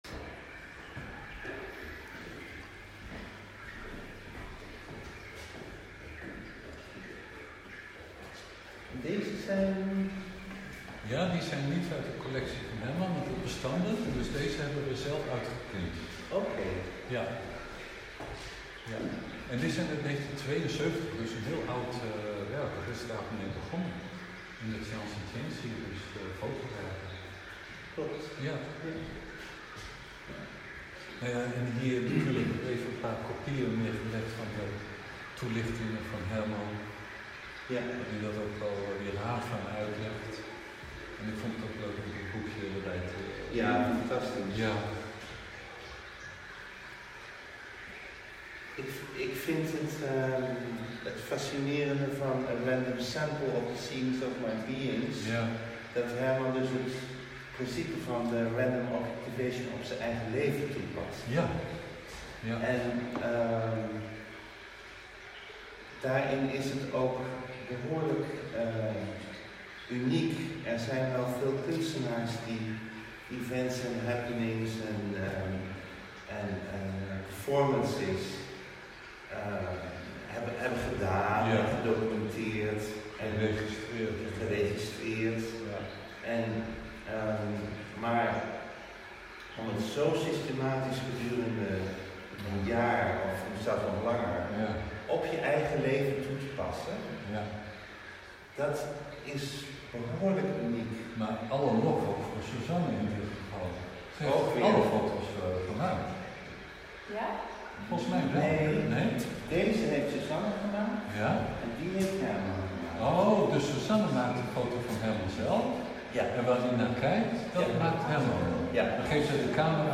Rondleiding